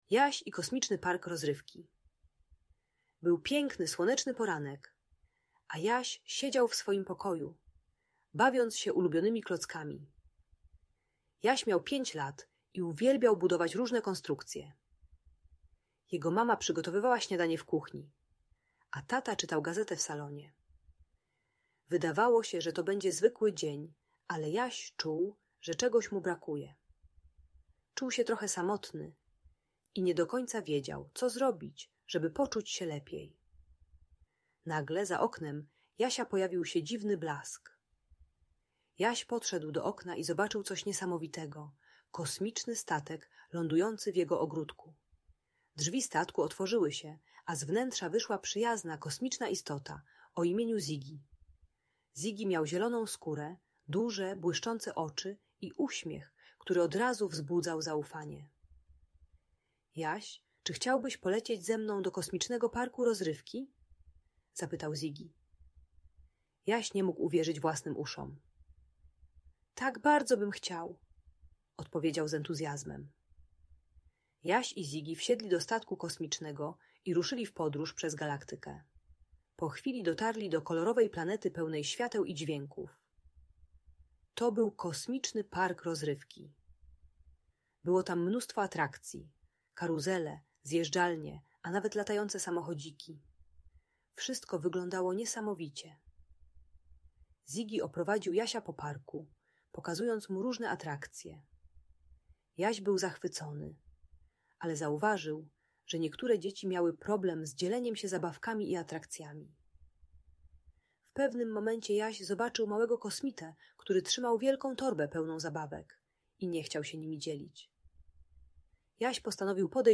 Jaś i Kosmiczny Park Rozrywki - Niepokojące zachowania | Audiobajka